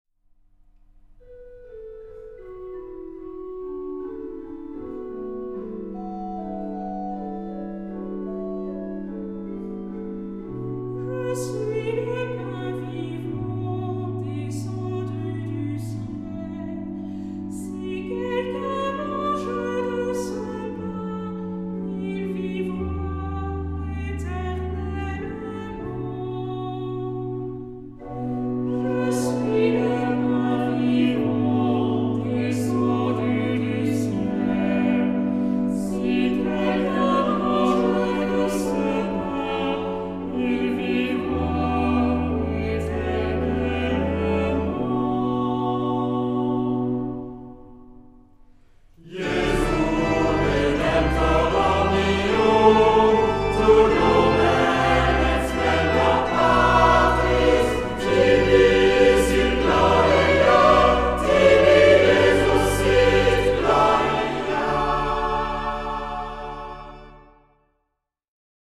Genre-Style-Forme : Tropaire ; Psalmodie
Caractère de la pièce : recueilli
Type de choeur : SATB  (4 voix mixtes )
Instruments : Orgue (1) ; Instrument mélodique (1)
Tonalité : ré majeur ; si mineur
Quatuor de solistes
Trompette